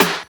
13 SNARE  -R.wav